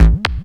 bseTTE52026hardcore-A.wav